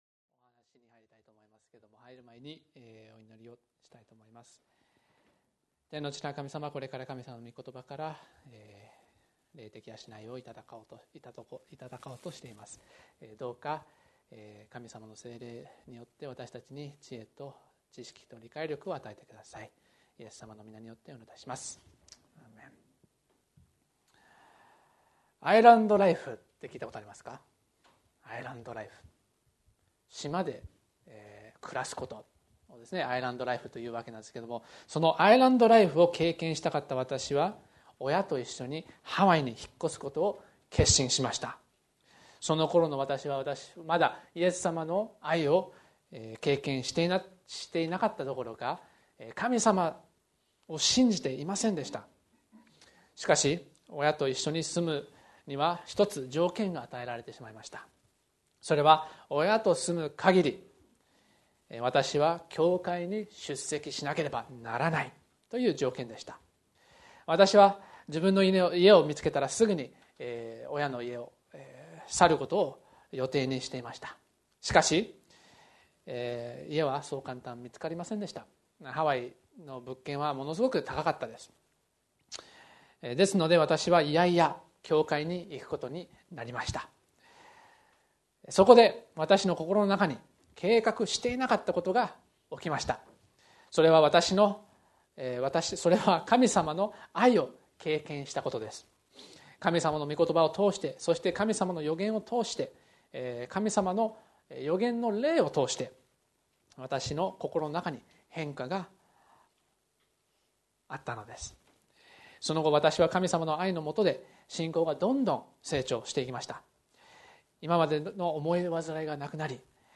礼拝説教